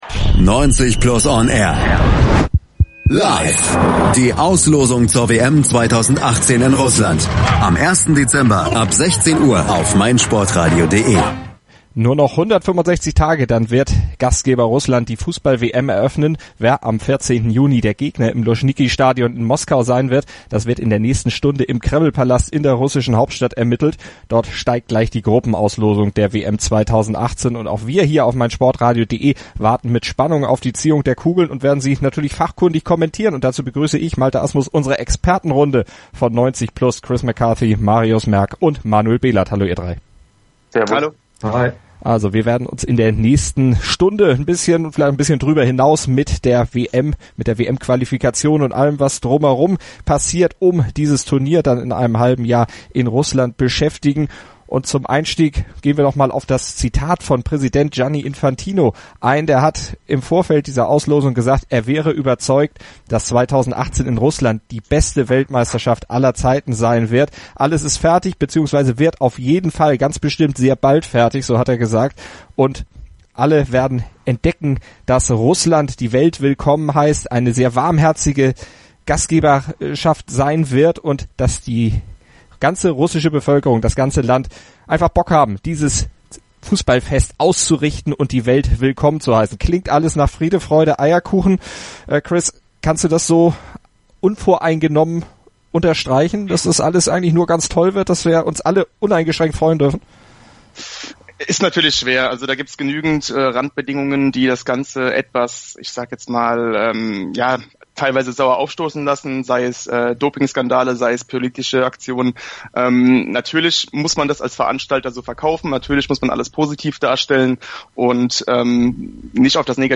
ab-16-uhr-live-analyse-der-wm-auslosung.mp3